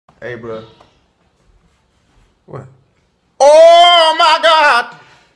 Play, download and share oh my gawwwwd original sound button!!!!
oooh-my-god-vine_V6J67Pl.mp3